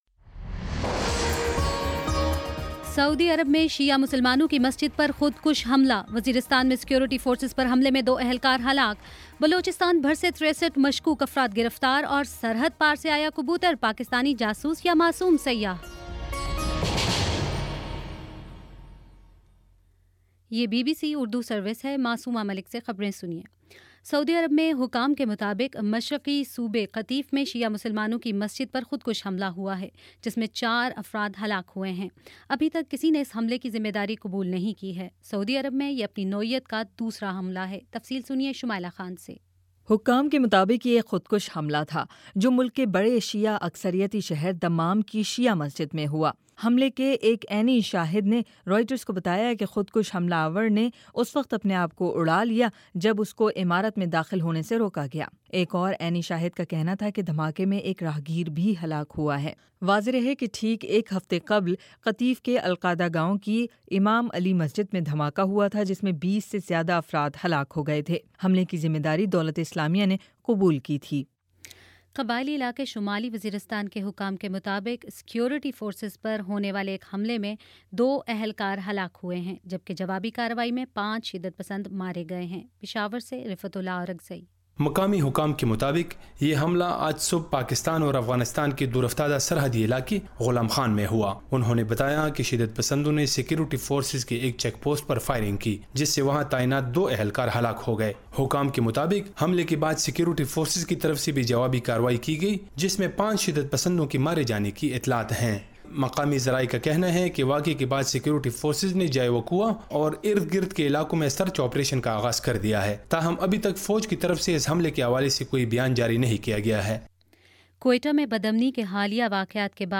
مئی 29: شام پانچ بجے کا نیوز بُلیٹن